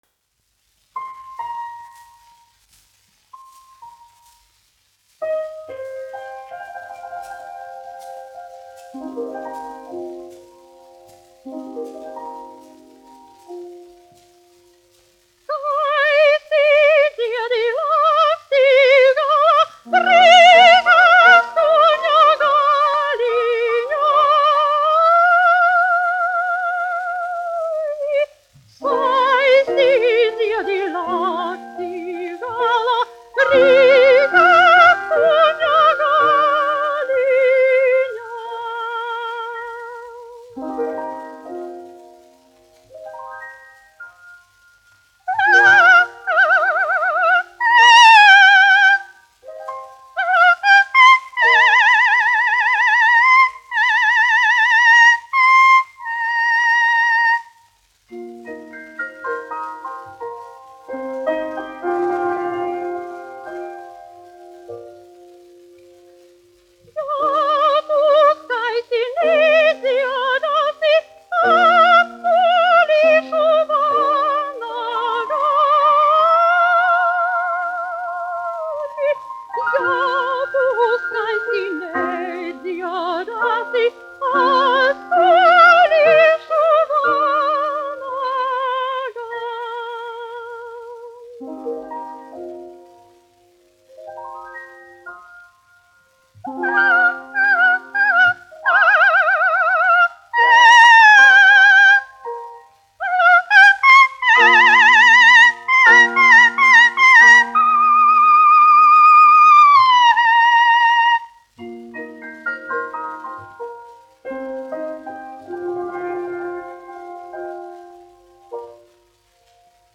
1 skpl. : analogs, 78 apgr/min, mono ; 25 cm
Latviešu tautasdziesmas
Skaņuplate